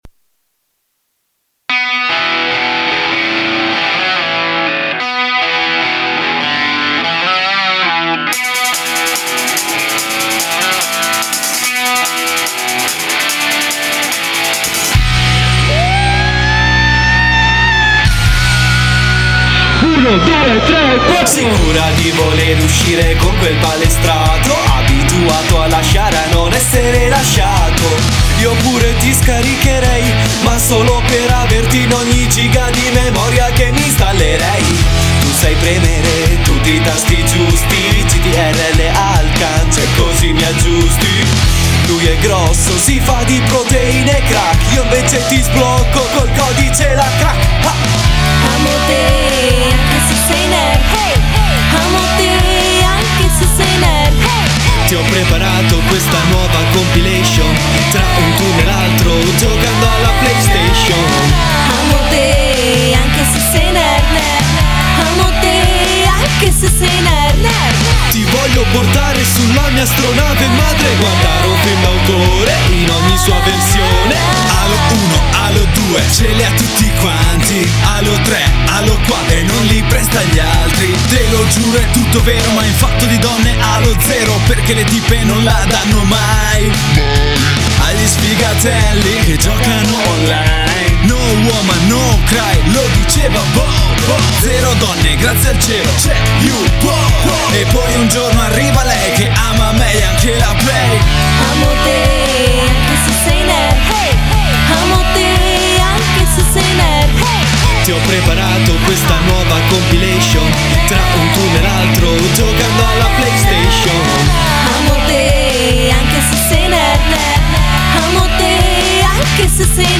GenereHip Hop / Rap